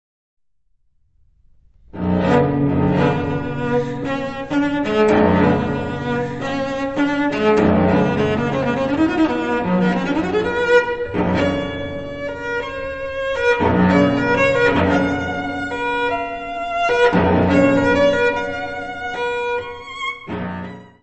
violoncelo.
: stereo; 12 cm
Área:  Música Clássica
Allegro maestoso ma appassionato.